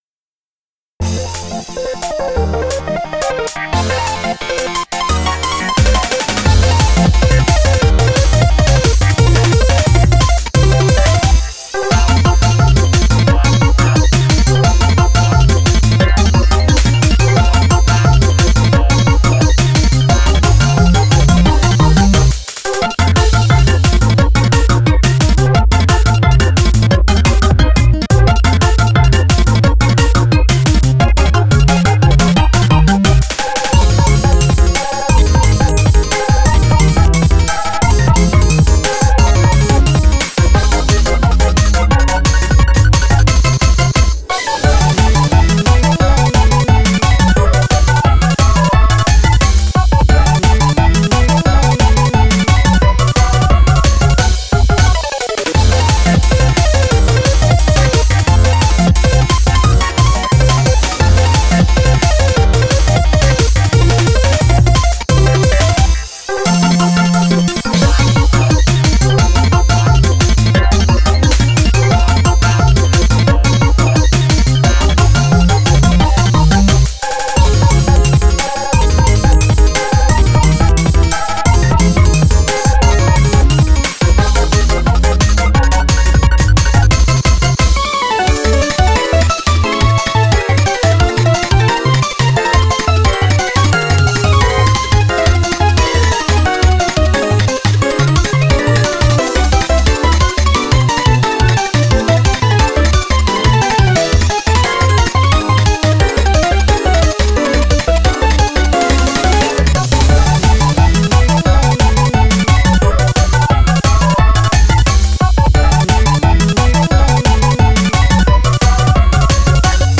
MP3 ohne Gesang: